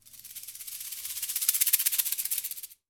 STR SHAKE -S.WAV